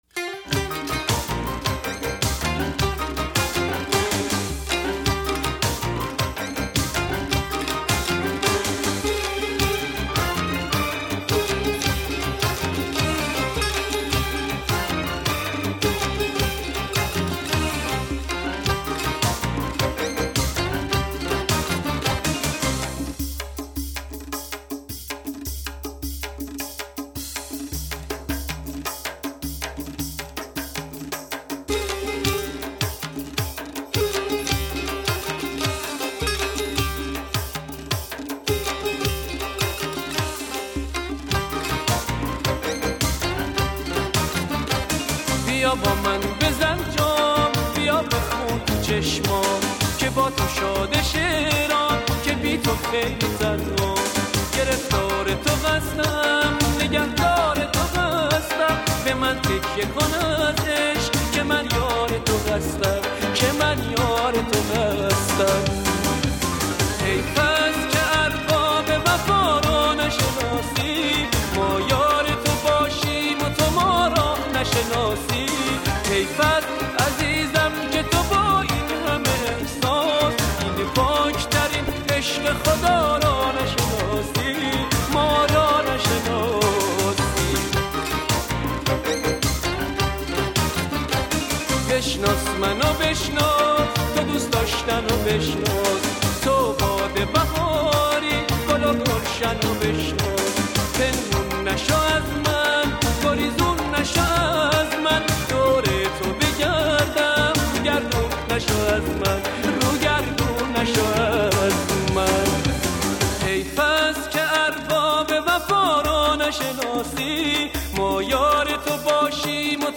آهنگ قدیمی
آهنگ قدیمی غمگین